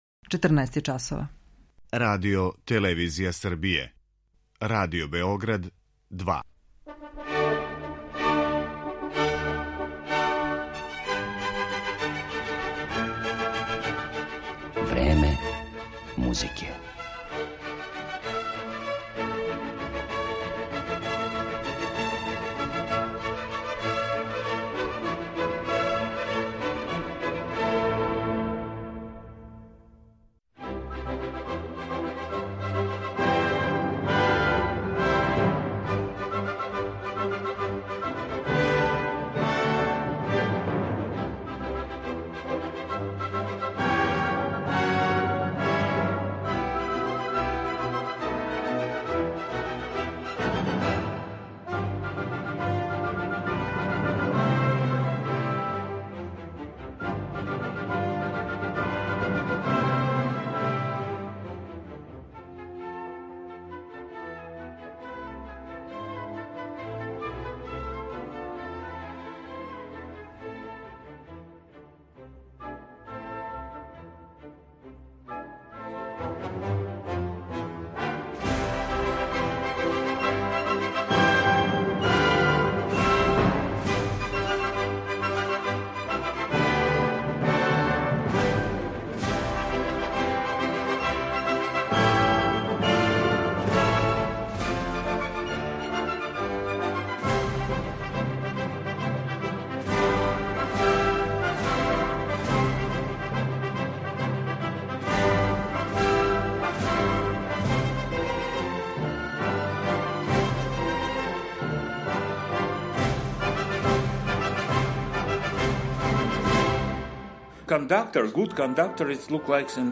Славном руском диригенту Михаилу Јуровском посвећена је данашња емисија у којој овог врхунског уметника представљамо и кроз ексклузивни интервју.
Јуровски важи за једног од најубедљивијих тумача музике Шостаковича, а поред ње слушаћемо га и у делима Николаја, Мајербера, Римког-Корсакова и Прокофјева. Такође, дириговаће оркестрима Радио Хановера, Западнонемачког радија из Келна и Берлинског радија.